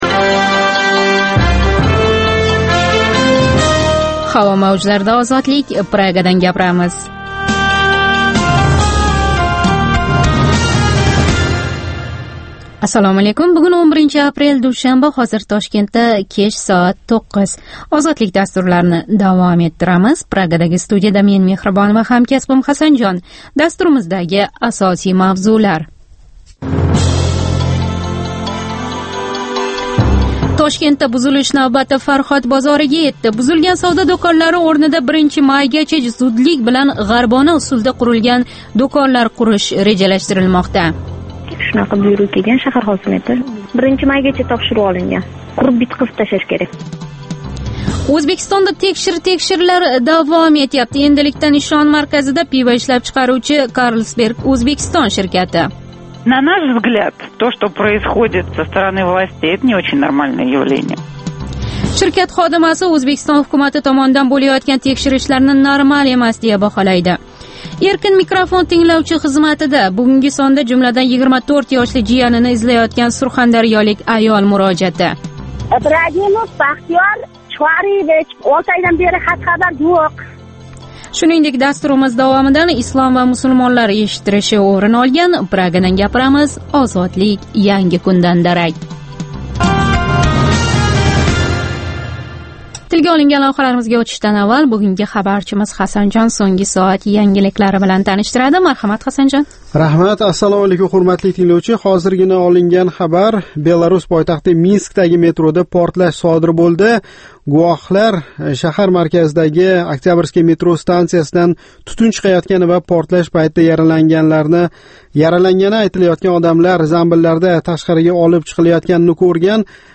Озодлик радиосининг Тошкент вақти билан тунги 9 да бошланадиган бу дастурида куннинг энг муҳим воқеаларига оид сўнгги янгиликлар¸ Ўзбекистон ва ўзбекистонликлар ҳаëтига доир лавҳалар¸ Марказий Осиë ва халқаро майдонда кечаëтган долзарб жараëнларга доир бойитилган тафсилот ва таҳлиллар билан таниша оласиз.